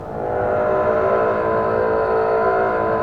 Index of /90_sSampleCDs/Roland L-CD702/VOL-1/STR_Cbs FX/STR_Cbs Sul Pont